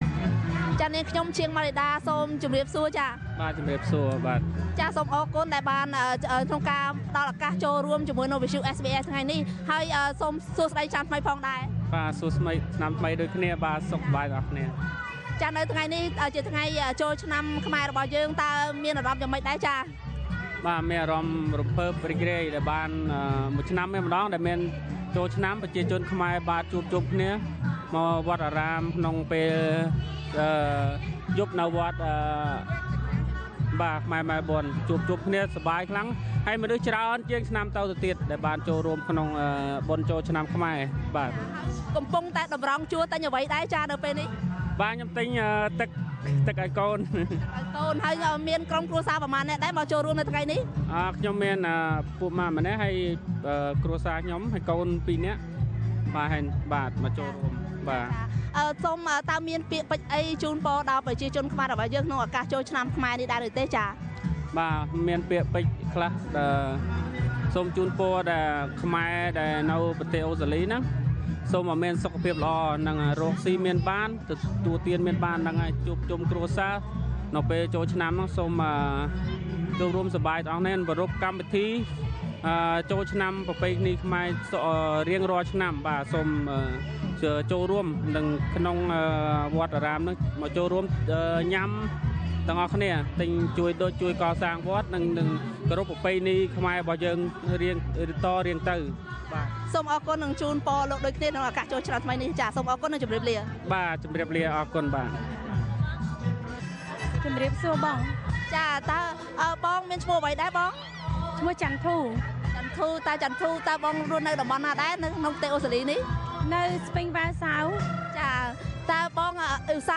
ពាក្យពេចន៍ជូនពររបស់ពលរដ្ឋខ្មែរដែលបានចូលរួមពិធីបុណ្យចូលឆ្នាំថ្មីប្រពៃណីជាតិខ្មែរនៅវត្តពុទ្ធរង្សីម៉ែលប៊ន កាលពីយប់ថ្ងៃចូលឆ្នាំដំបូង៕
2017 Khmer New Year in Wat Putraingsey Melbourne Source: SBS Khmer